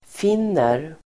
Uttal: [f'in:er]